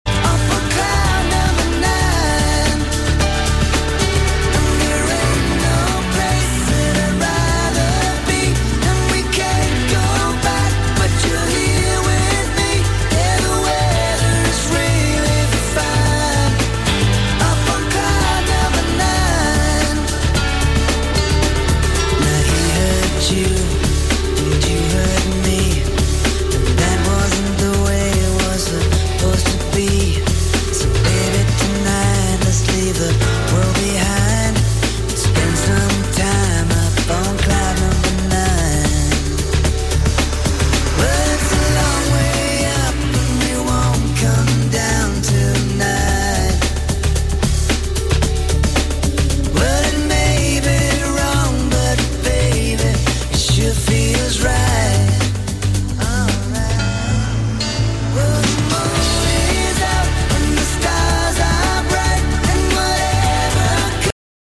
В этой аудиозаписи фрагмент одной песни: